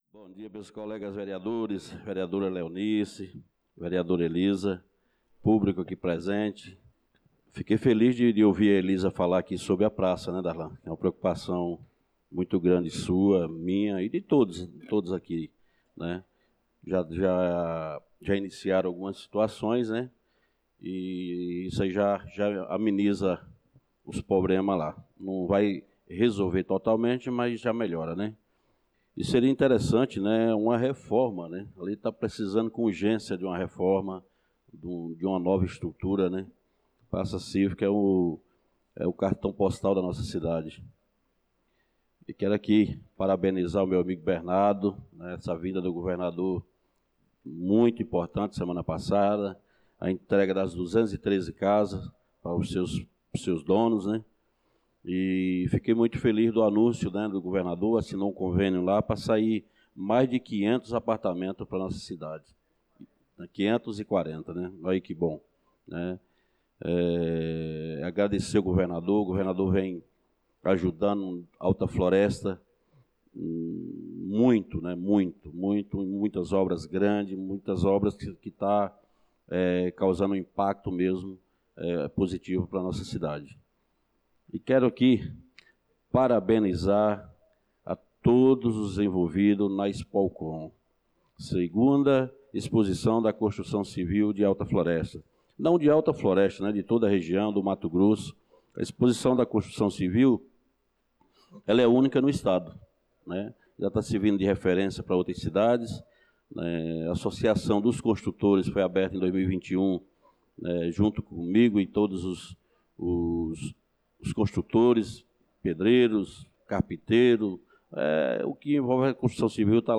Pronunciamento do vereador Francisco Ailton na Sessão Ordinária do dia 09/06/2025